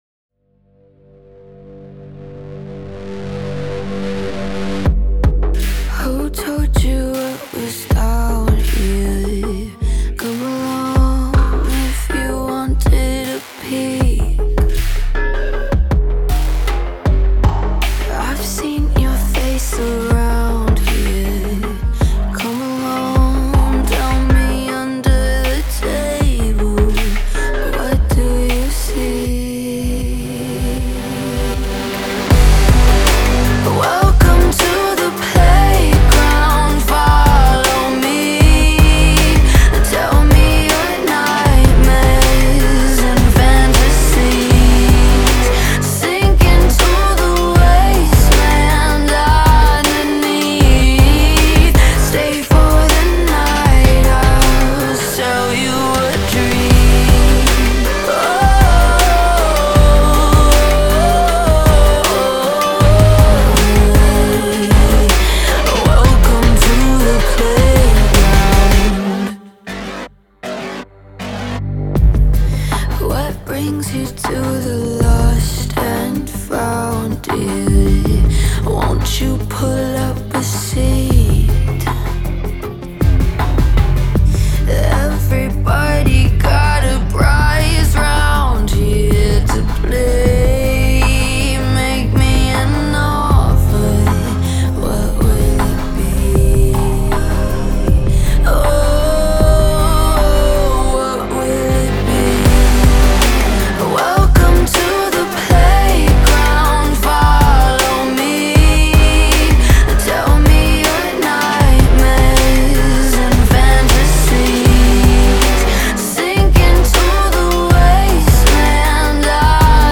Genre : Soundtrack